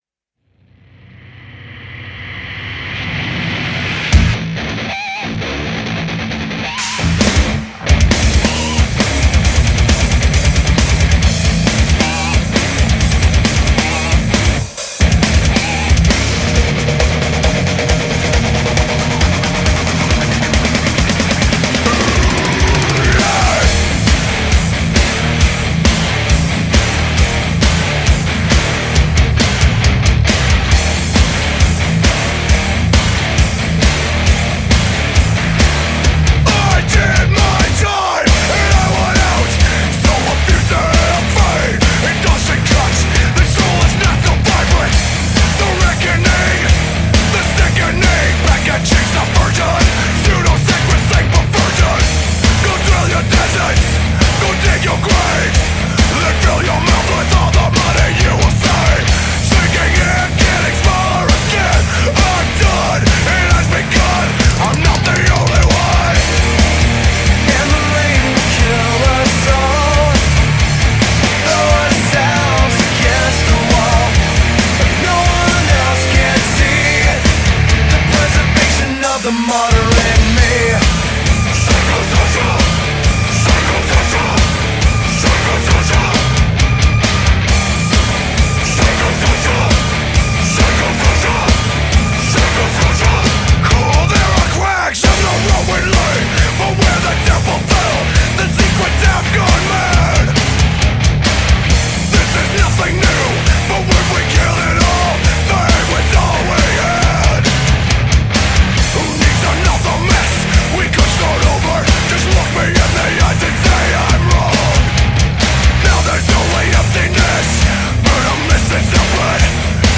2025-01-03 17:23:56 Gênero: Rock Views